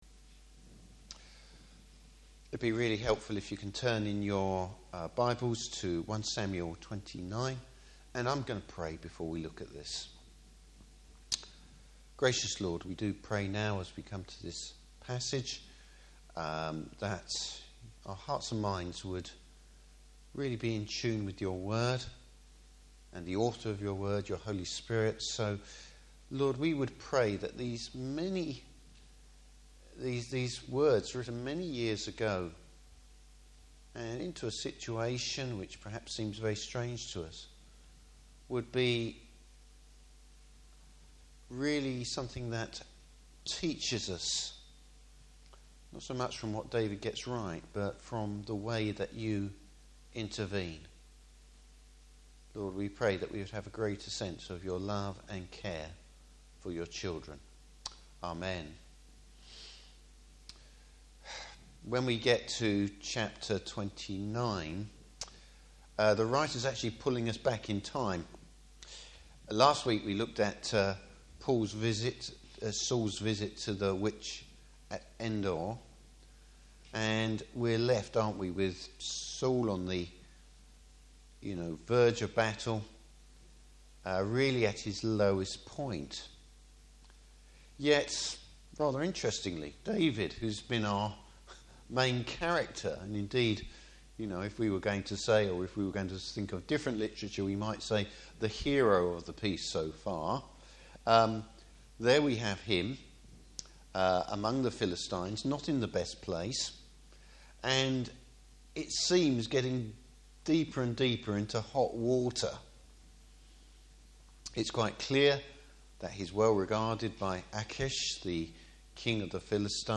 Service Type: Morning Service The Lord’s providence saves David from his mistake.